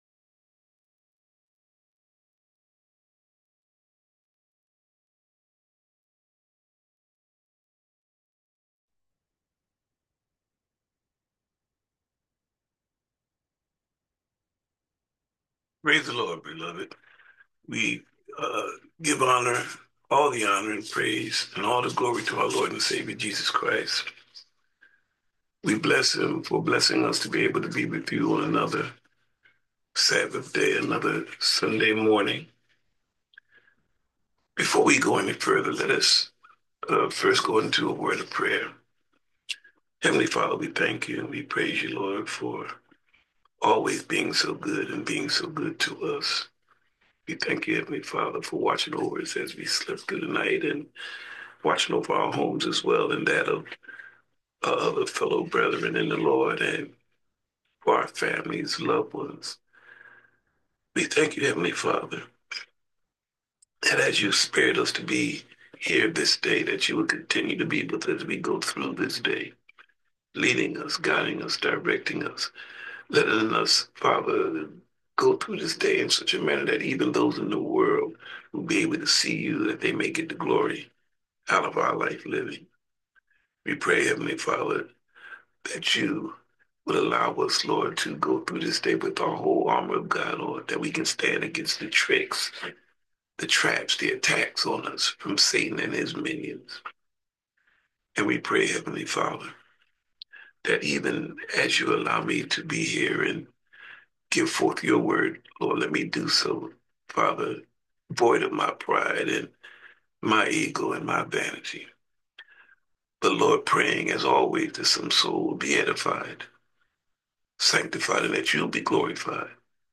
In God We Trust Because We Must - Sermon - St James Missionary Baptist Church